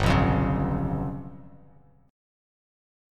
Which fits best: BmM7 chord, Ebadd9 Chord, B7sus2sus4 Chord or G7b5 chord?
G7b5 chord